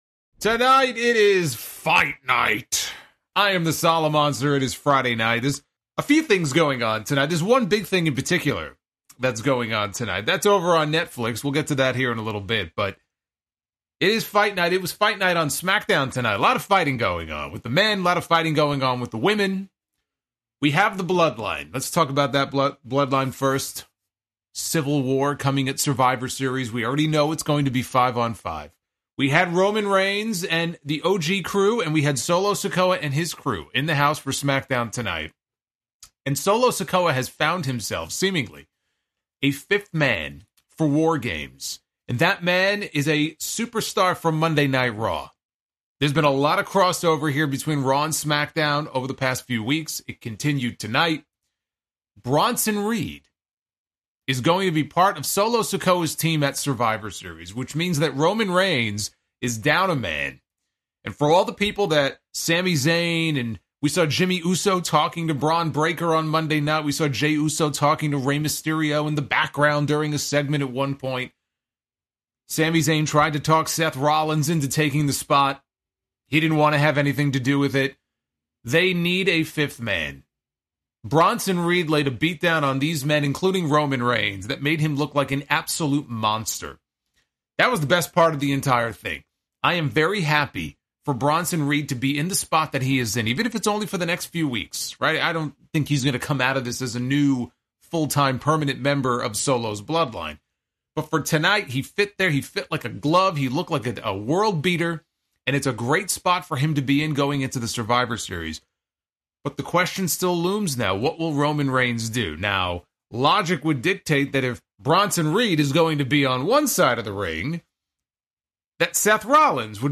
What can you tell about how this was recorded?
Mixed in with the review is a live WATCH ALONG for the Mike Tyson vs. Jake Paul fight on Netflix and what a shit show it was.